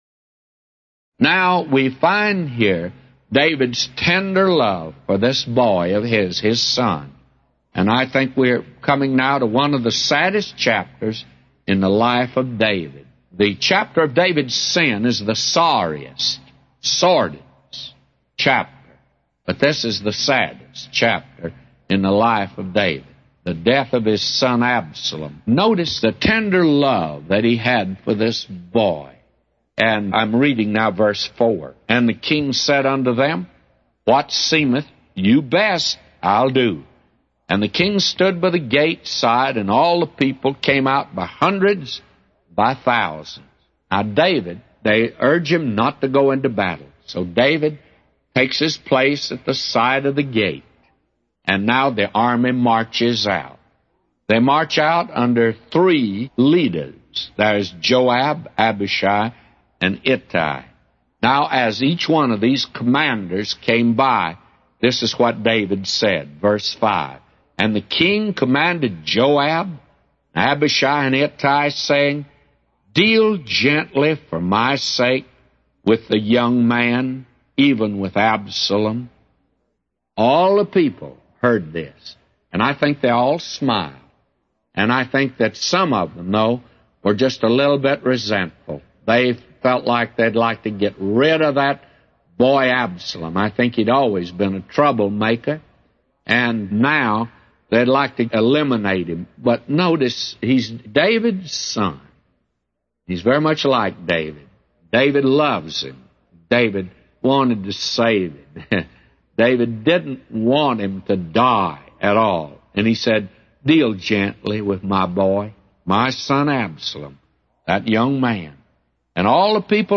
A Commentary By J Vernon MCgee For 2 Samuel 18:4-999